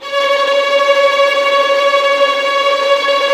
Index of /90_sSampleCDs/Roland L-CD702/VOL-1/STR_Vlns Tremelo/STR_Vls Tremolo